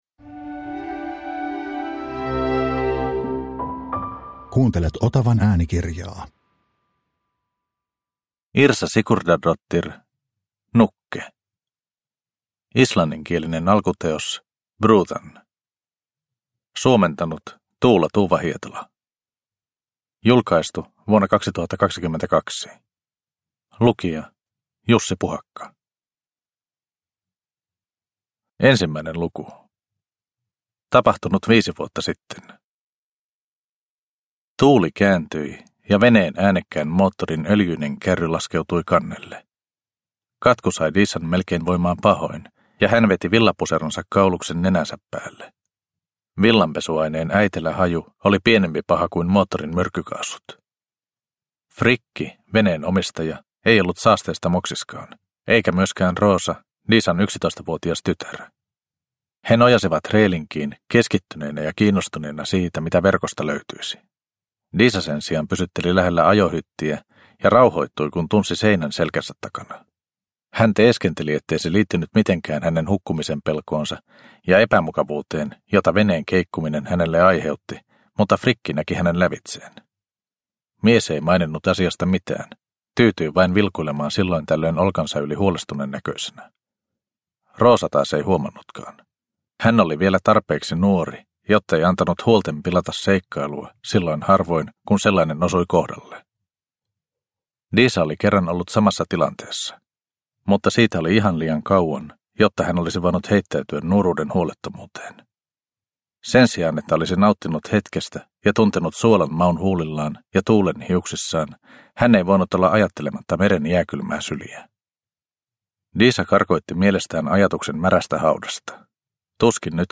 Nukke – Ljudbok – Laddas ner